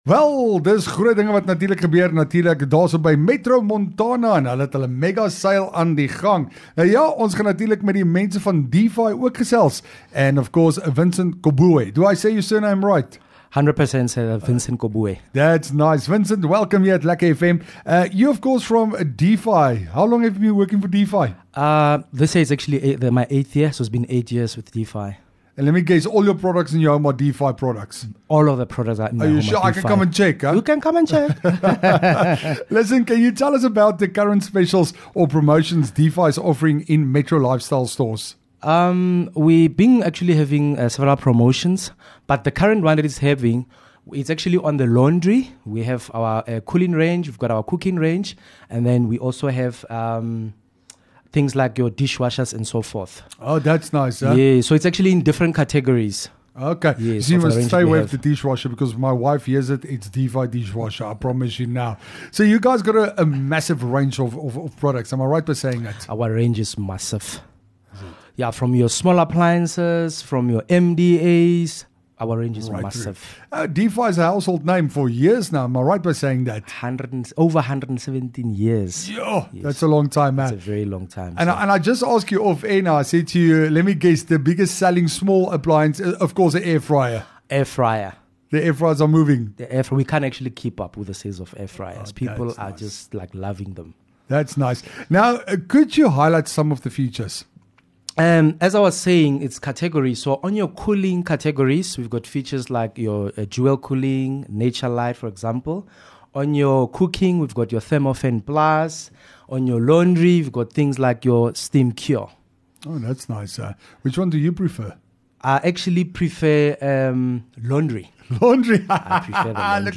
LEKKER FM | Onderhoude